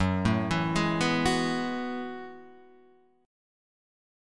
F#m11 chord